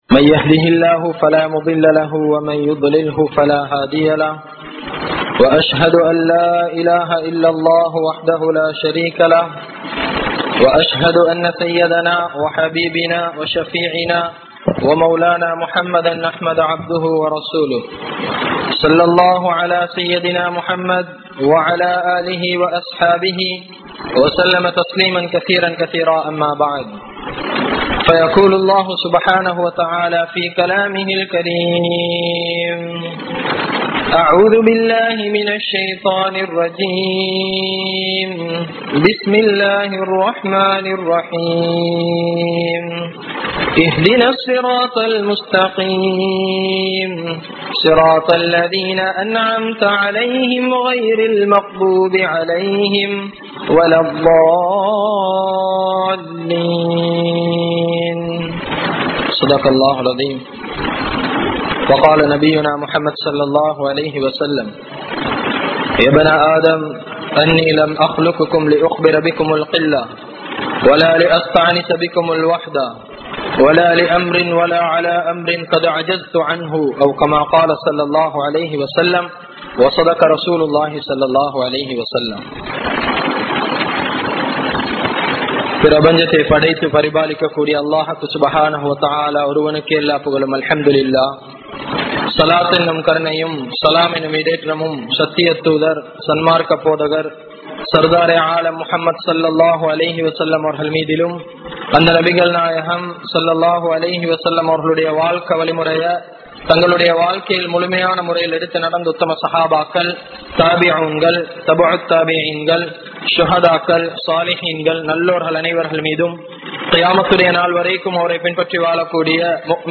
Paavangal Soathanaihalai Kondu Varum (பாவங்கள் சோதனைகளை கொண்டு வரும்) | Audio Bayans | All Ceylon Muslim Youth Community | Addalaichenai
Gothatuwa, Jumua Masjidh